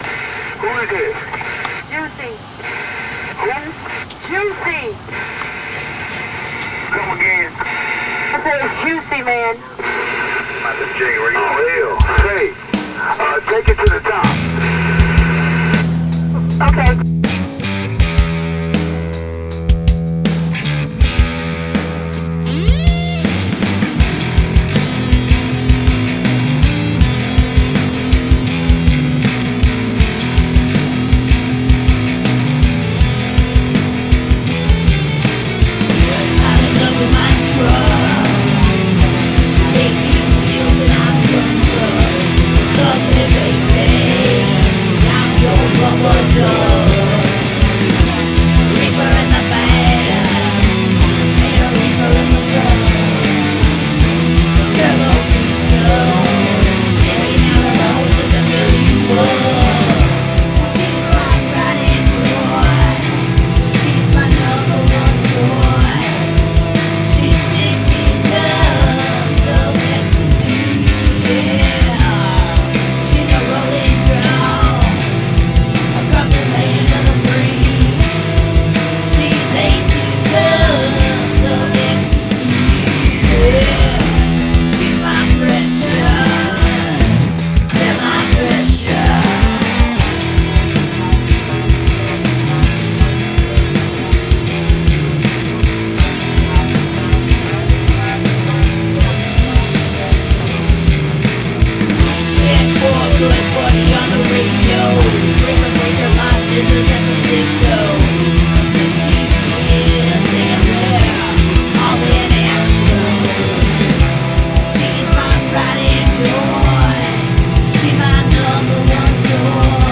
Gradual emphasis of repetitions
bass sounds great on this.